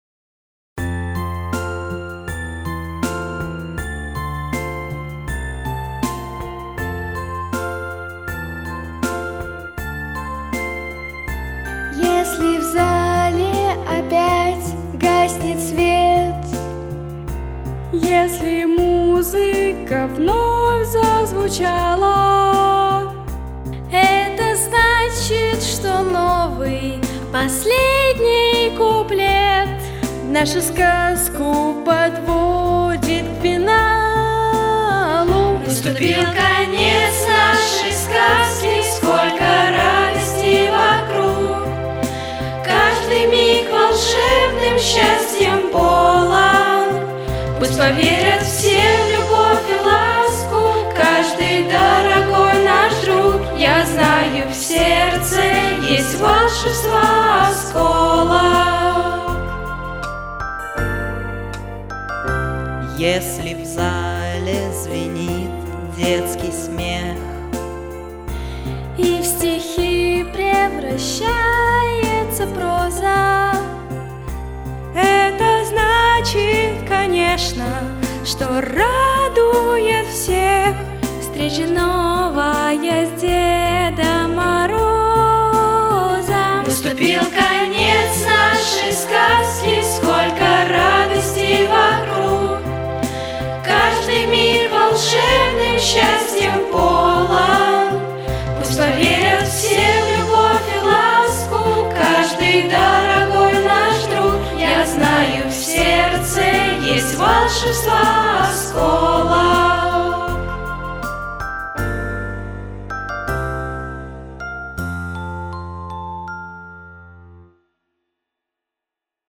Записано в студии Easy Rider в декабре 2019 года